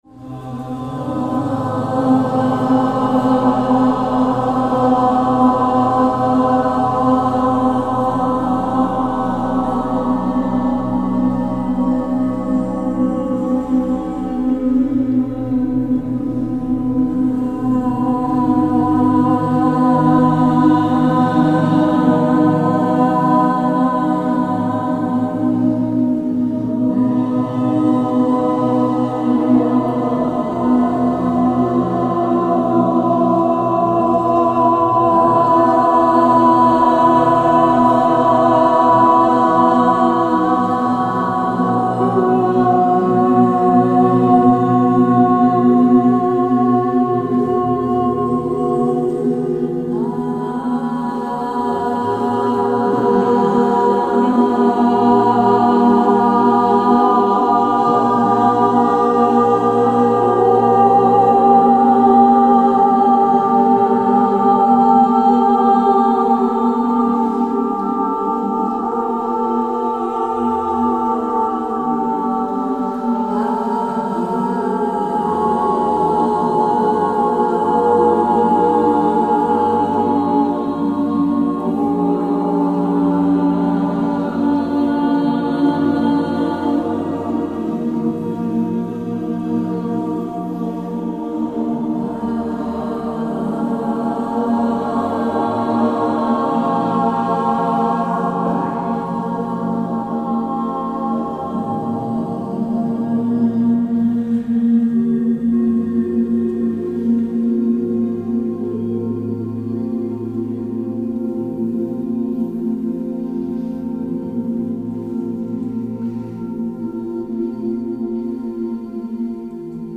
Seminar Vitaltönen am 14.03.2020 in Mülheim/Ruhr
Verschmelzen im Klang mit den TeilnehmerInnen des Seminars Vitaltönen im Treppenhaus der Backsteinschule mit einem schönen natürlichen Hall.